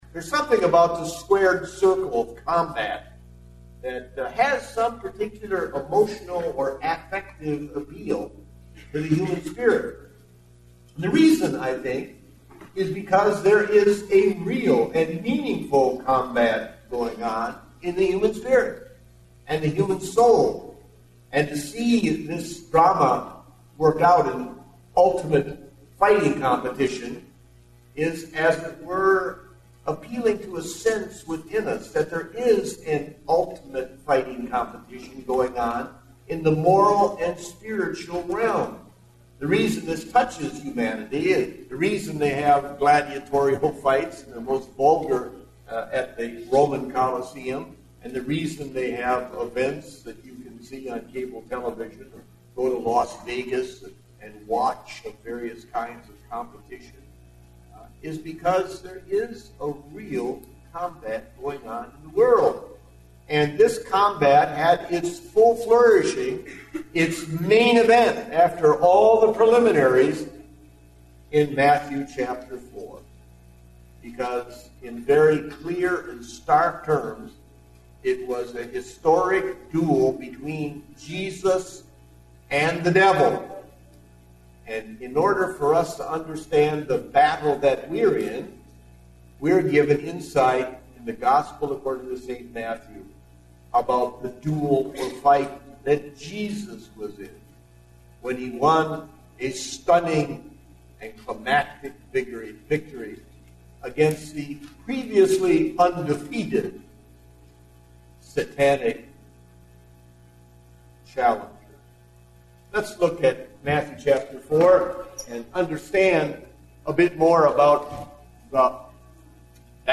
Date: January 10, 2010 (Morning Service)